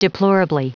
Prononciation du mot : deplorably
deplorably.wav